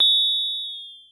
Vermona DRM 1 » Vermona Hi Hat 10
描述：From the Hi Hat Channel of the Vermona DRM 1 Analog Drum Synthesizer
标签： Analog Vermona DRM 1 Synth Drum Sample
声道立体声